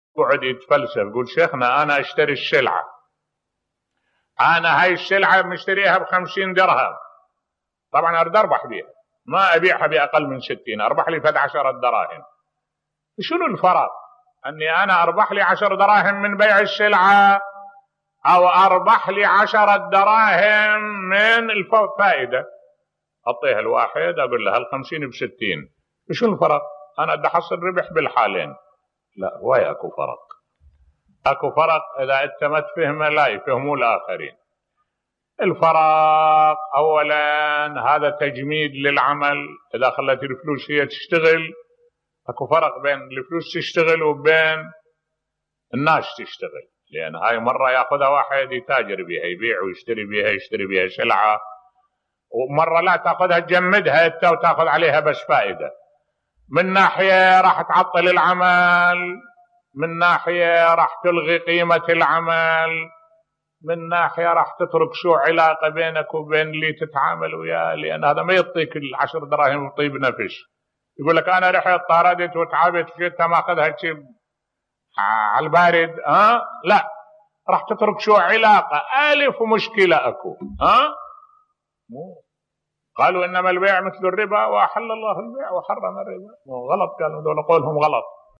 ملف صوتی لماذا يحرم الله الربا بصوت الشيخ الدكتور أحمد الوائلي